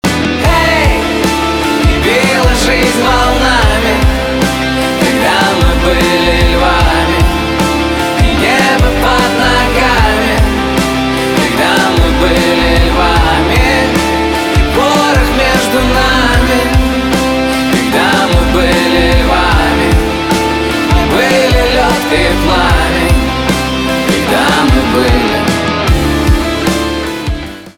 русский рок , гитара , барабаны , пианино , грустные